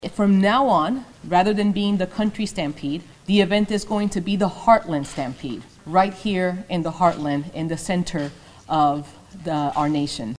Along with that will be a name change, which Topeka Mayor Michelle De La Isla announced at a news conference Thursday.